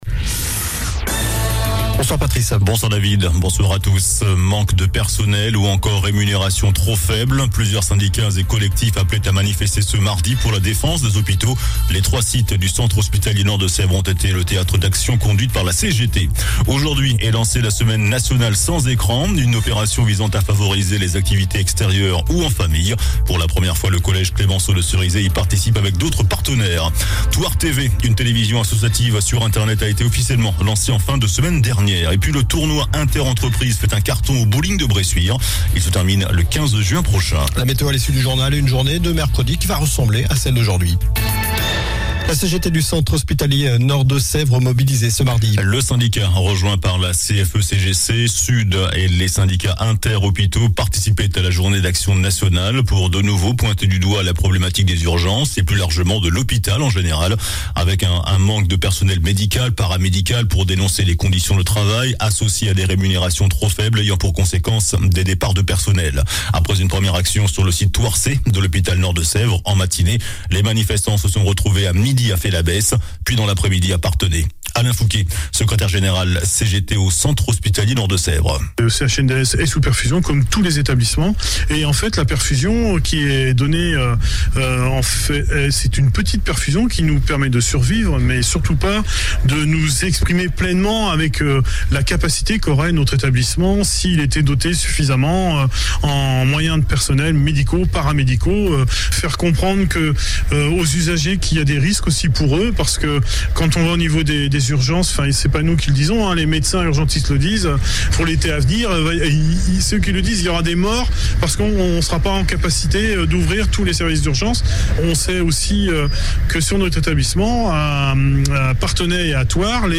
JOURNAL DU MARDI 07 JUIN ( SOIR )